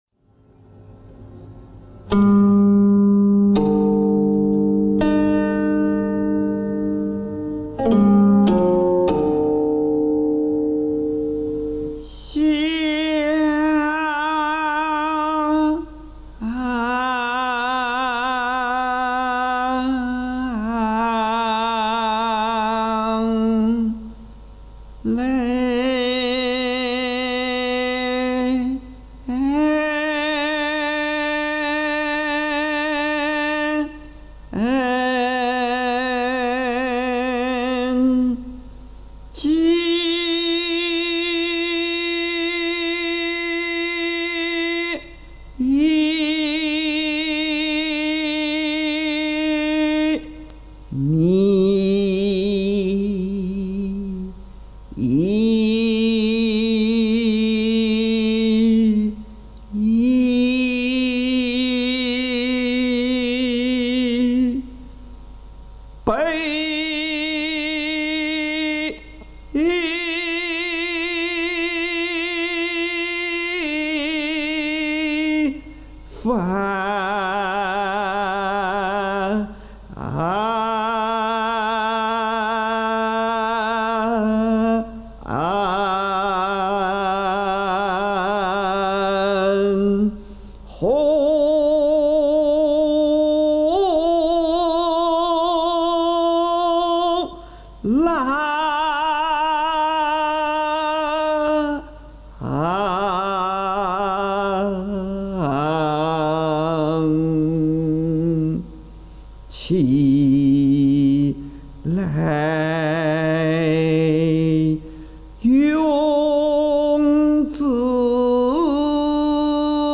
吟唱